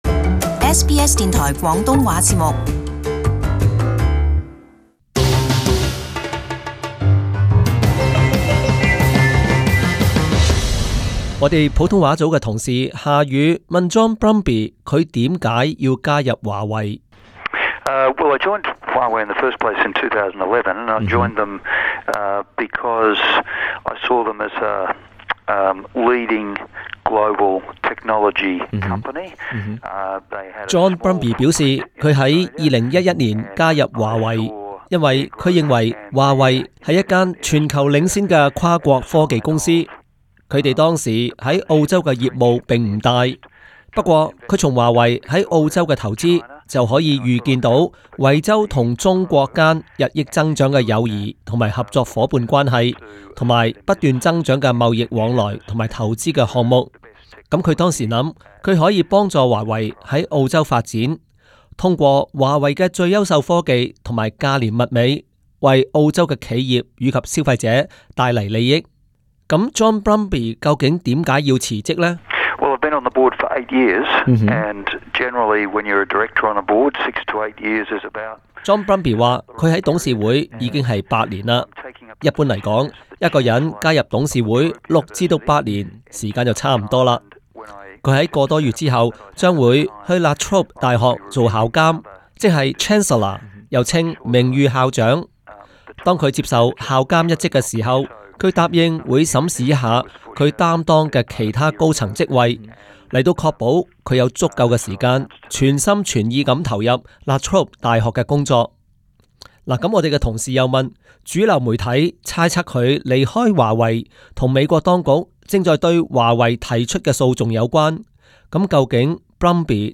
【SBS專訪】華為澳洲董事會高層John Brumby：辭職決定與華為被告無關